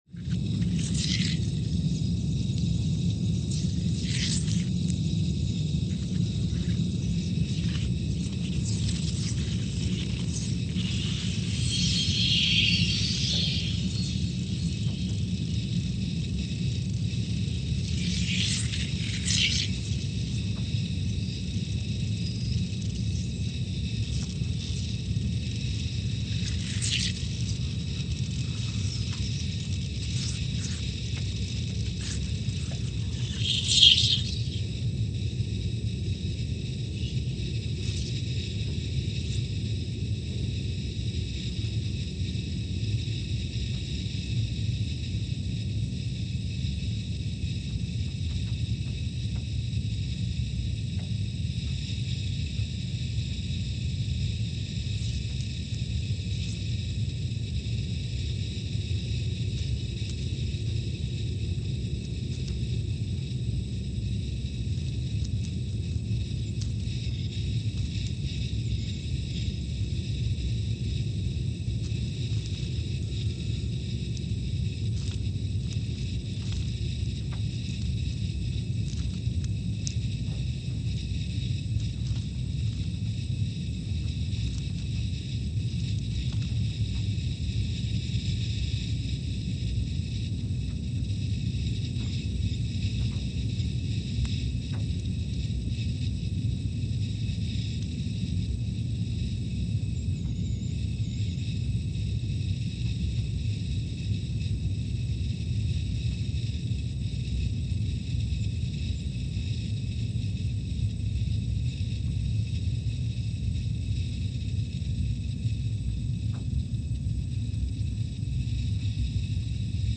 Scott Base, Antarctica (seismic) archived on August 3, 2022
Sensor : CMG3-T
Speedup : ×500 (transposed up about 9 octaves)
Loop duration (audio) : 05:45 (stereo)
SoX post-processing : highpass -2 90 highpass -2 90